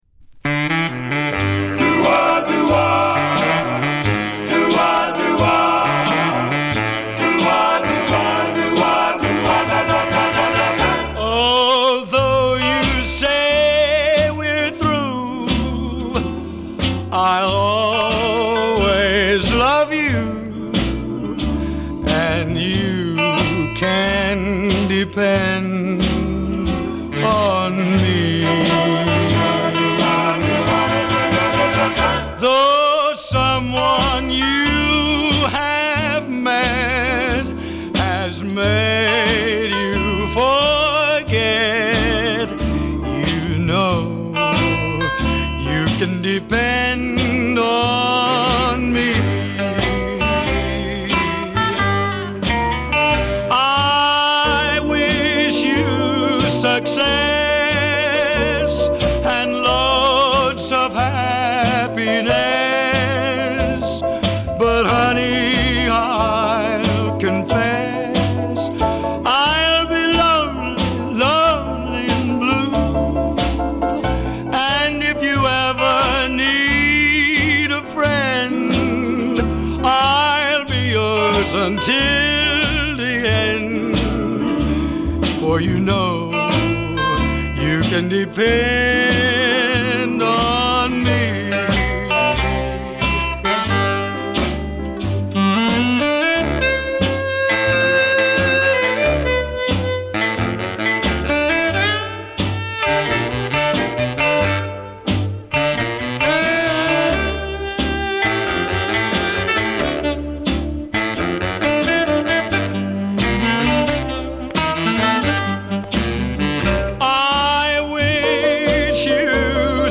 ポピュラー・ミュージック黎明期から活動するカントリー〜ポップ〜ロックンロール・シンガー。